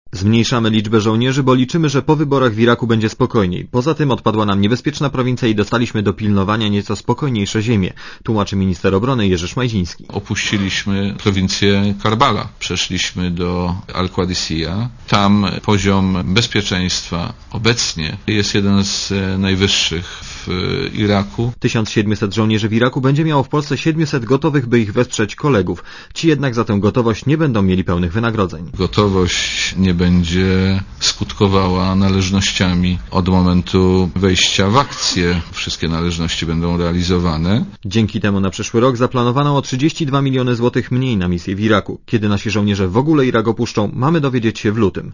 relacji